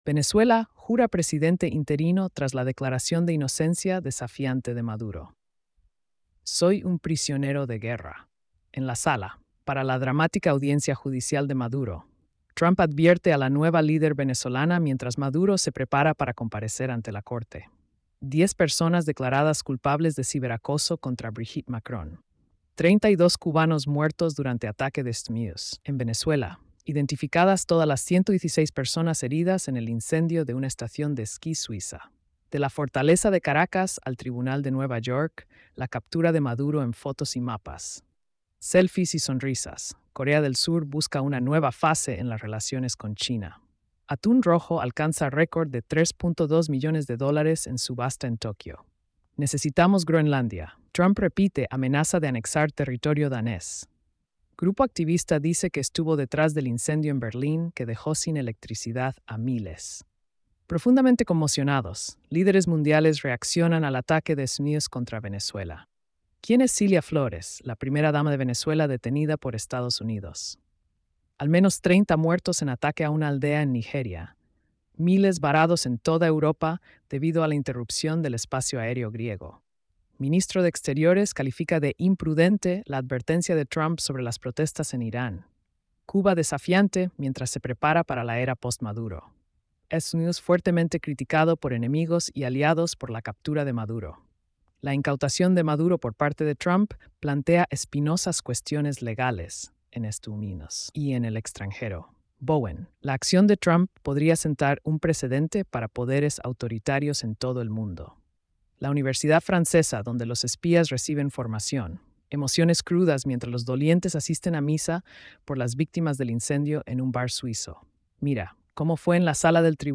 🎧 Resumen de noticias diarias.